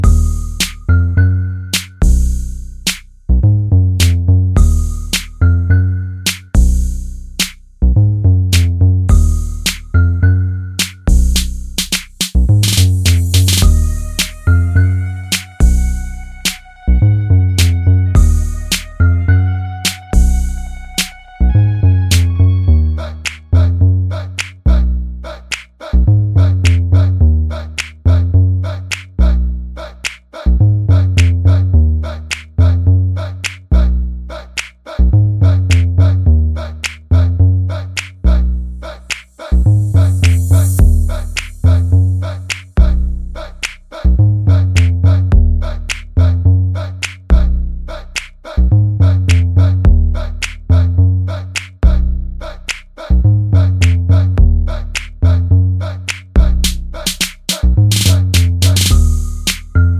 for solo male Pop (2010s) 3:23 Buy £1.50